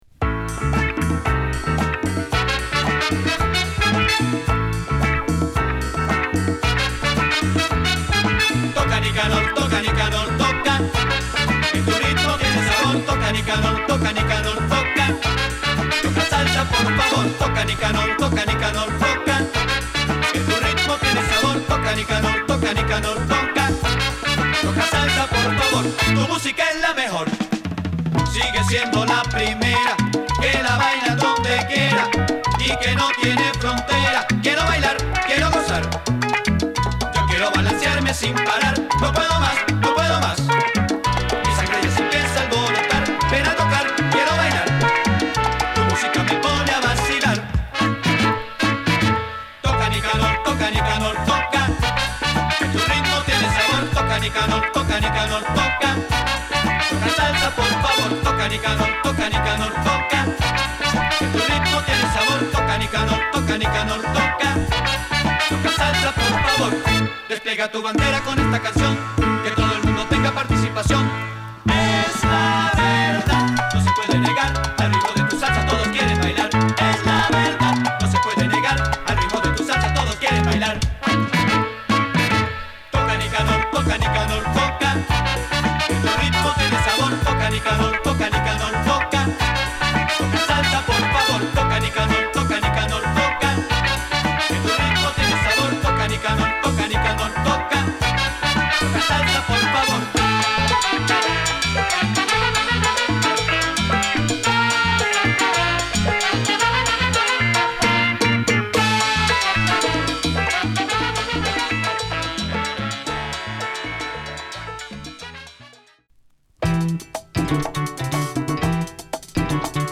クンビア〜ラテンレアグルーヴ好作！